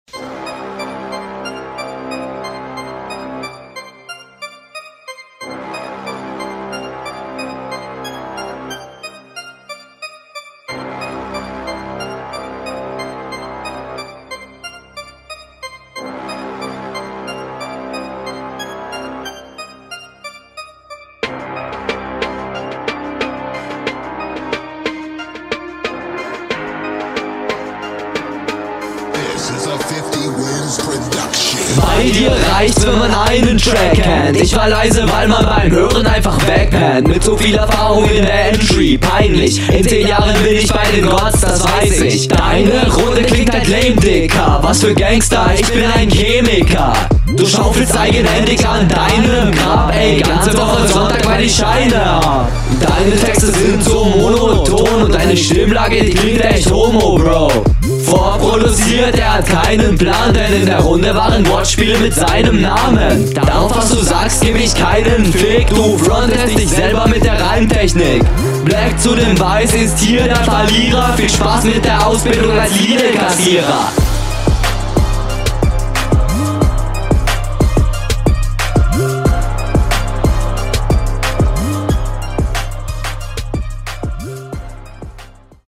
Ich muss sagen, dass ich ziemlich überrascht war, da die Mische diesmal meiner Meinung ziemlich …
Das ist leider echt unschön gemischt, ich versteh dadurch kein Wort.